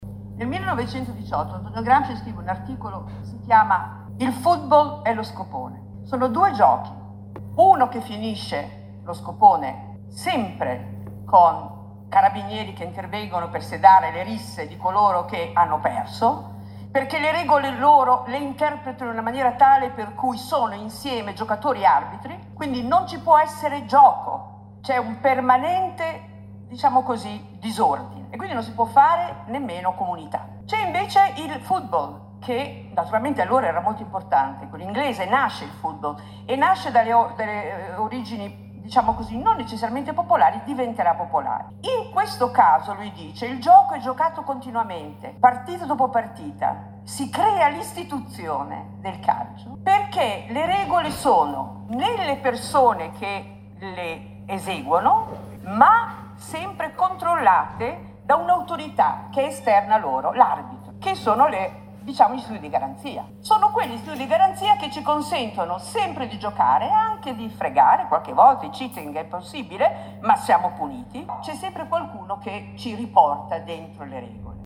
Ascoltiamo Nadia Urbinati della Columbia University che racconta un aneddoto esemplare di Antonio Gramsci sulle regole e sul gioco.